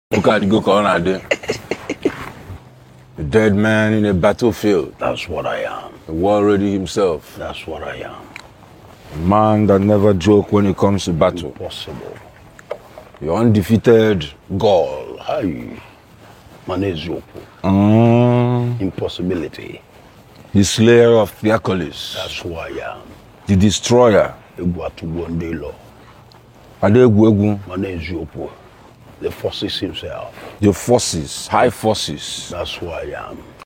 smooth Afrobeat rhythms with heartfelt emotions